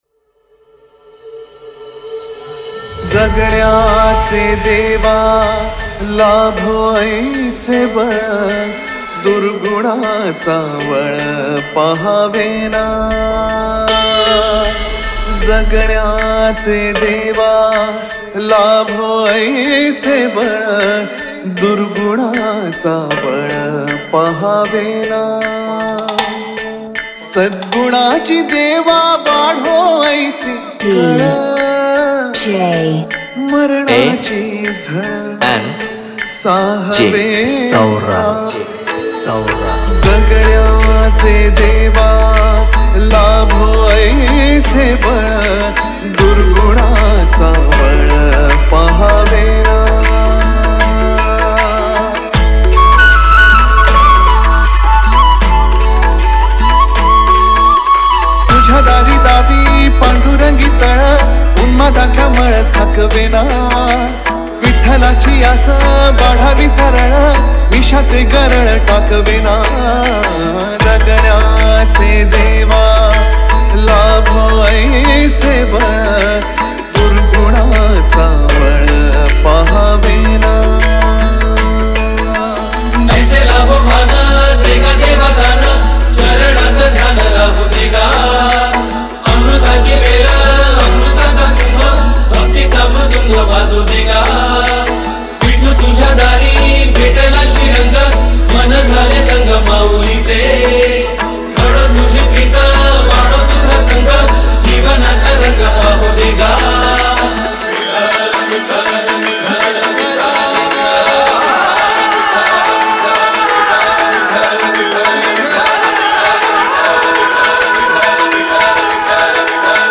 Marathi Dj Single 2025
Marathi Sound Check 2025